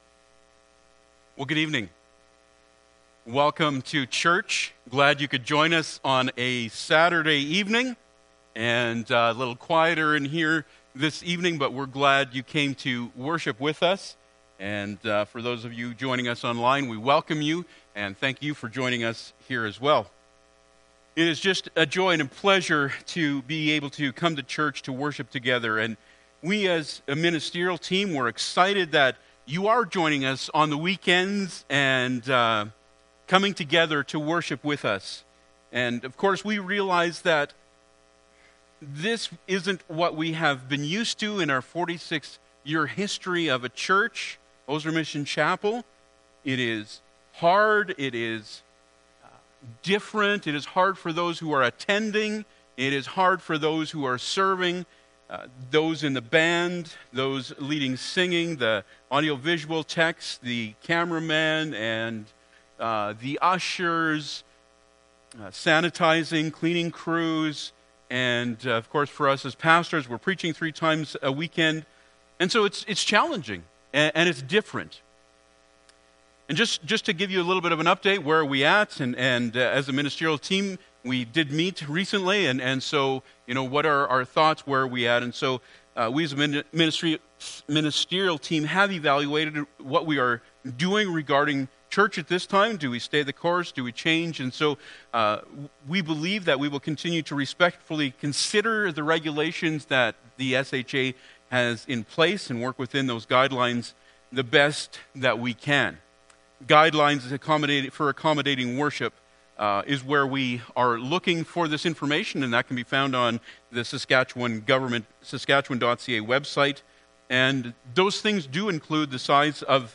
Bible Text: Luke 10:27 | Preacher